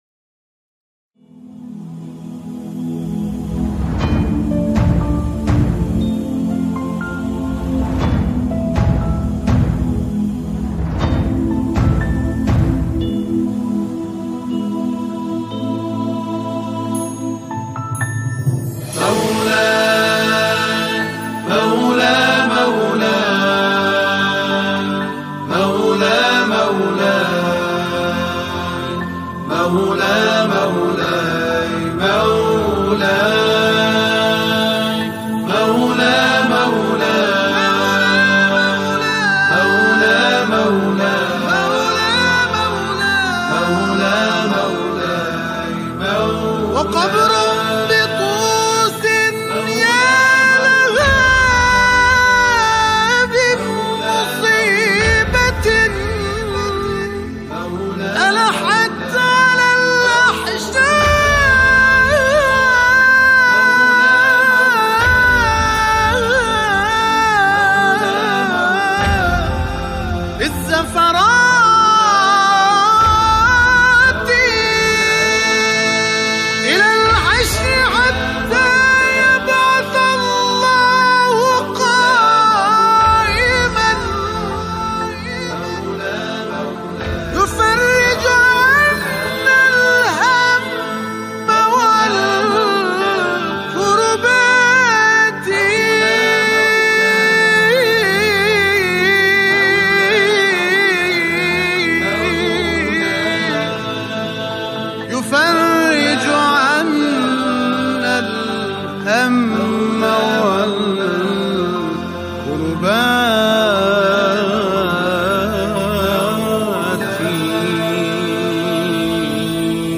همخوانی
گروهی از همخوانان